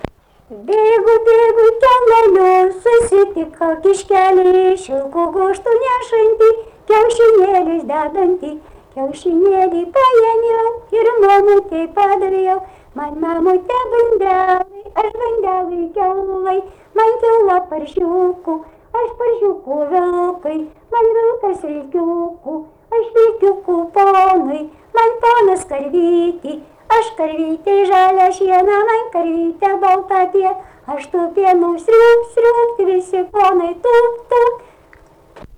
smulkieji žanrai
Bagdoniškis
vokalinis